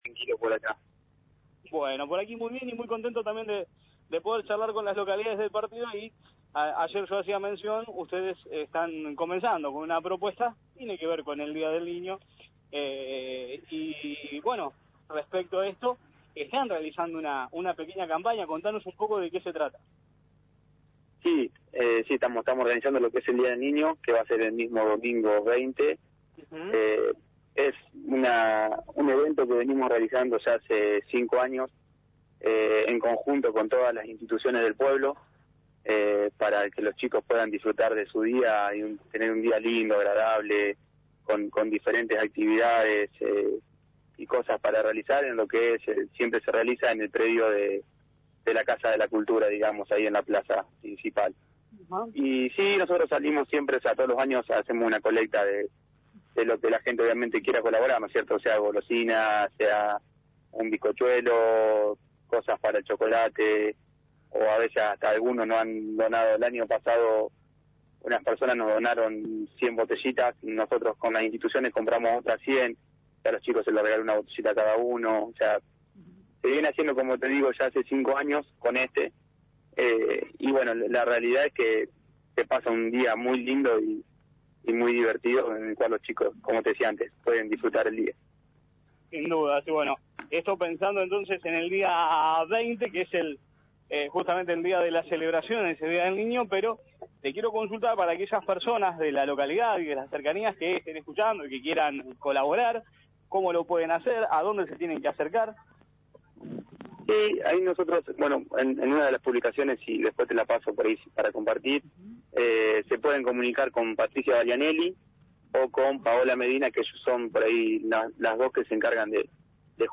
El delegado municipal de Guerrico Iván De Curtis, dialogó con el móvil de «La Mañana de la Radio» este martes, dónde comentó acerca de la colecta solidaria que vienen realizando pensando en los festejos del día del niño.